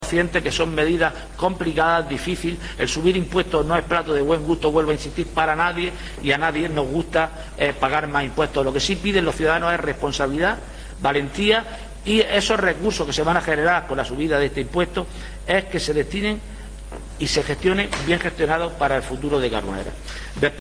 Alcalde.mp3